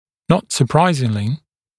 [nɔt sə’praɪzɪŋlɪ][нот сэ’прайзинли]неудивительно